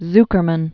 (zkər-mən), Pinchas Born 1948.